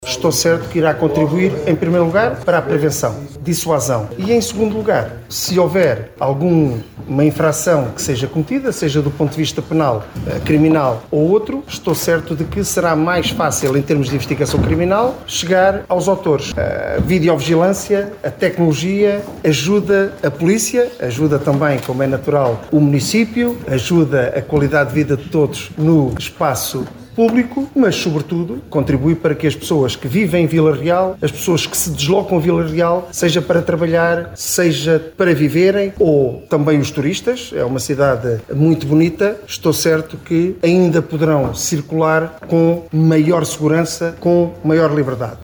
Trata-se de um investimento de cerca de 730 mil euros que vai reforçar o sentimento de segurança na população, sendo um meio auxiliar para a manutenção da segurança e da ordem públicas, bem como para a prevenção da prática de factos qualificados na lei como crime, como reforçou o Diretor Nacional da Polícia de Segurança Pública, Superintendente-Chefe Luís Carrilho: